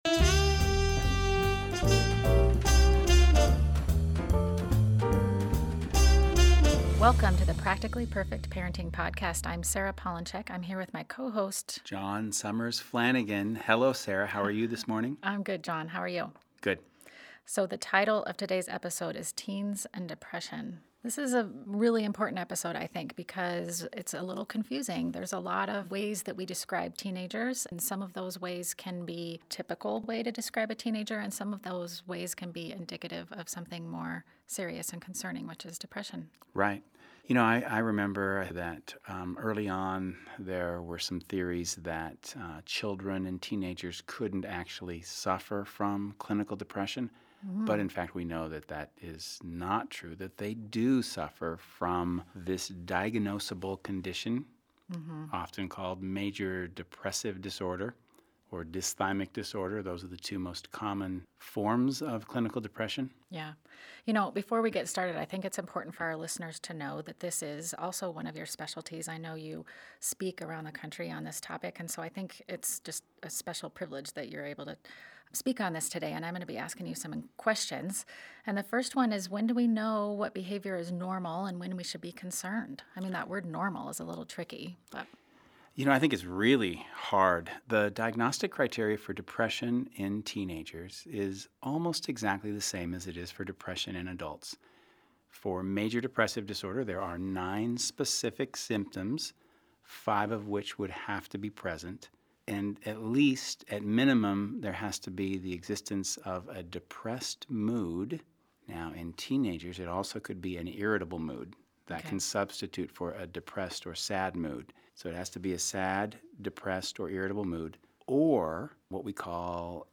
There are no laughs or giggles